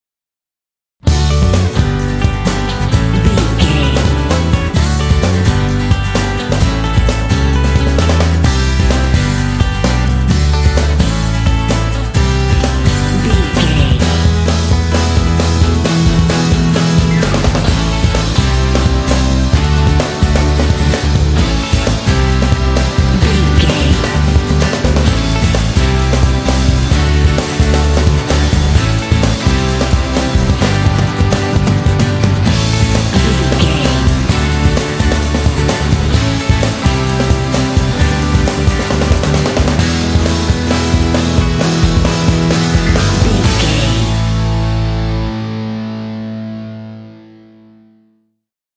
Uplifting
Ionian/Major
D
Fast
driving
bouncy
happy
groovy
motivational
drums
bass guitar
acoustic guitar
electric guitar
piano
pop
rock
contemporary underscore